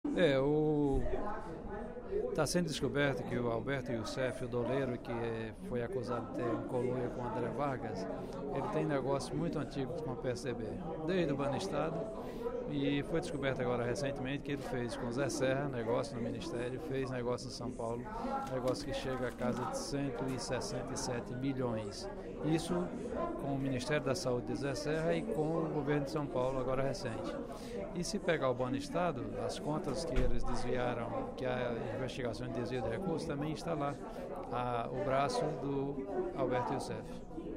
Durante o primeiro expediente da sessão plenária desta quarta-feira (14/05), o deputado Professor Pinheiro (PT) criticou a grande imprensa por dar pesos diferenciados na cobertura de fatos envolvendo o PT e o PSDB, citando como exemplo a operação Lava Jato da Polícia Federal.